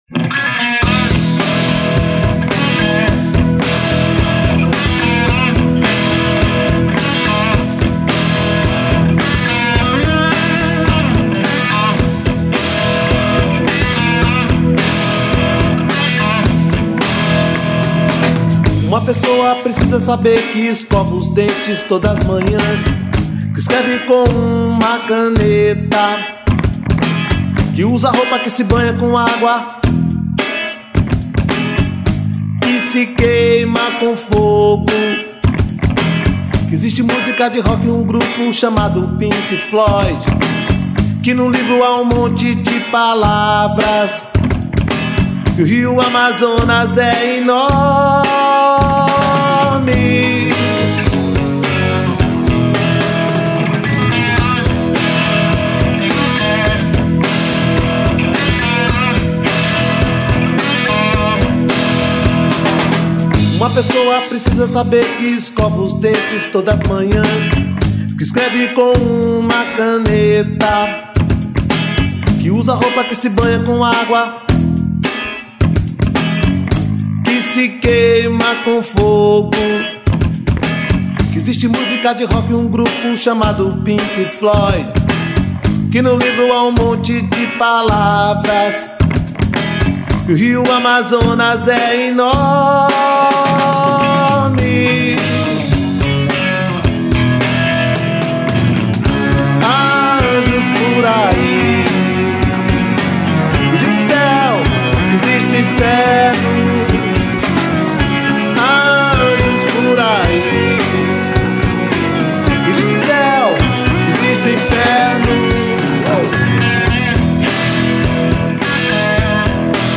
1344   04:02:00   Faixa:     Rock Nacional